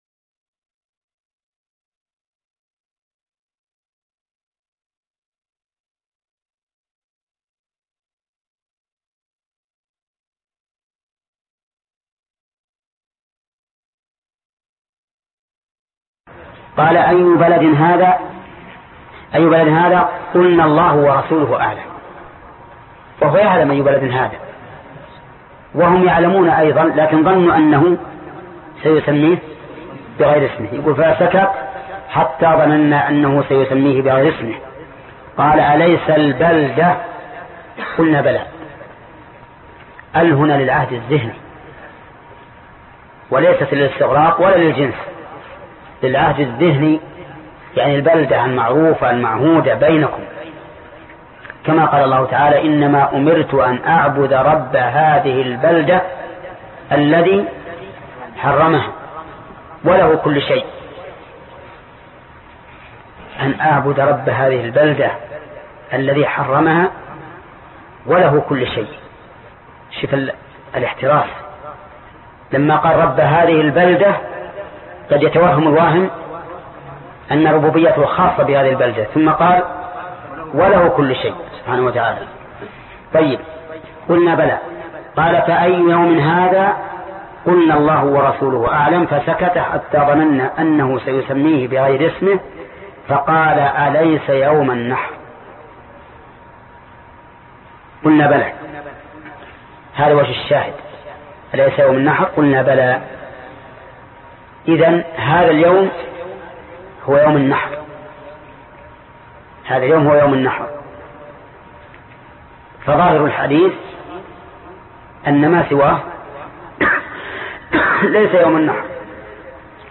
الدرس 6 باب من قال الأضحى يوم النحر3 (شرح كتاب الأضاحى صحيح البخارى) - فضيلة الشيخ محمد بن صالح العثيمين رحمه الله